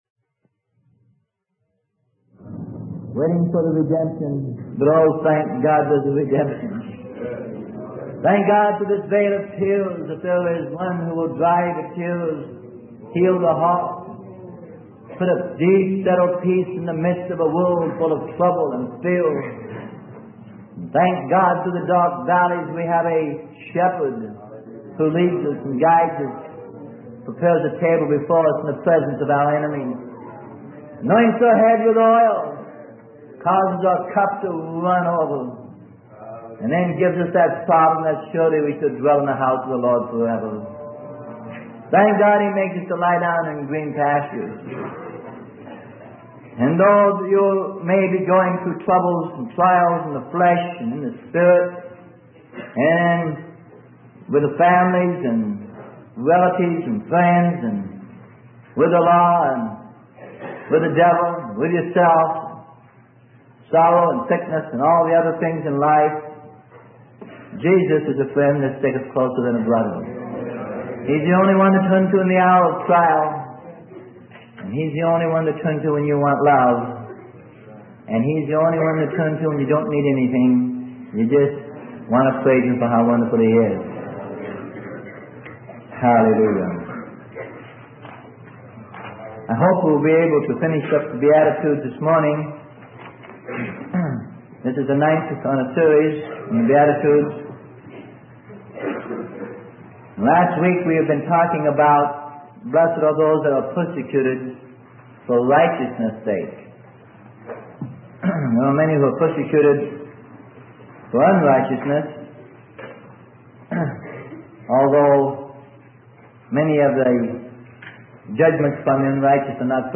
Sermon: The Beatitudes - Intro to Part 10: I PETER 1:7.